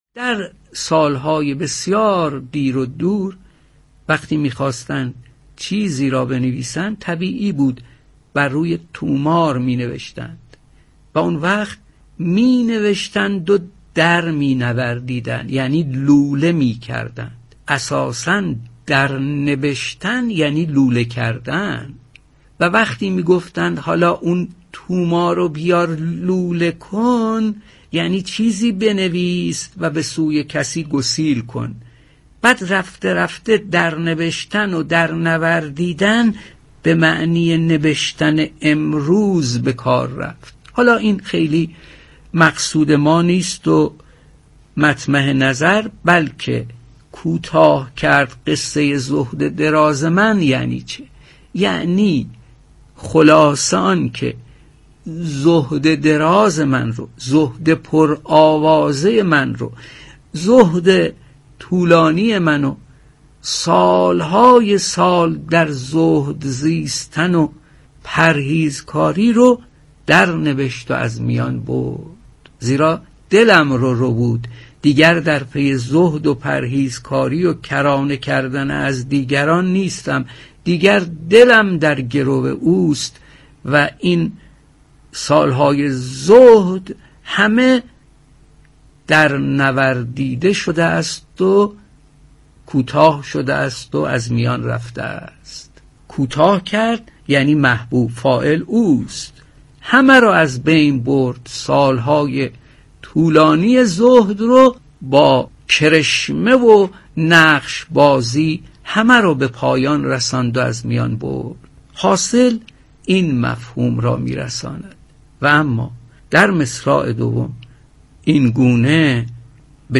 در این بخش می‌توانید شنونده صدای حسین آهی باشید.